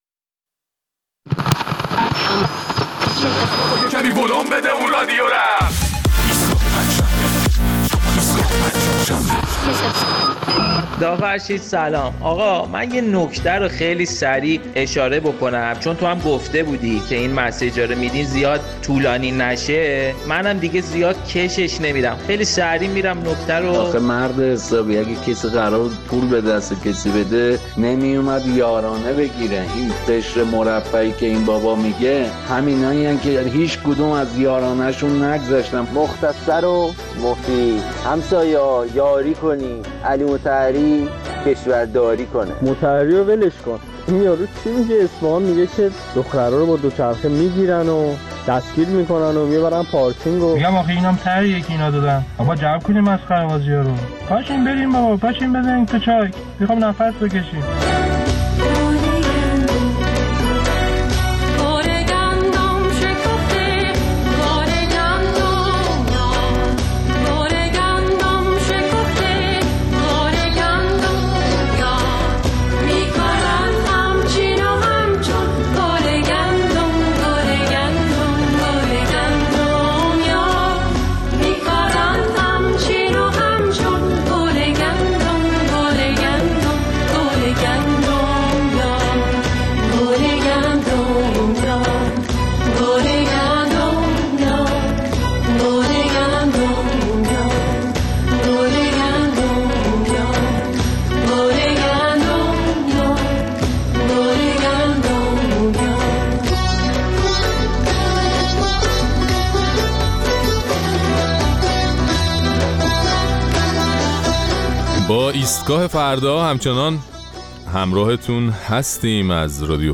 در این برنامه نظر مخاطبین‌مان را درباره اظهارات علی مطهری که به خانواده‌های توانمند ایرانی توصیه کرده بود برای طی شدن برهه حساس کنونی به خانواده‌های فقیرتر کمک کنند می‌شنویم.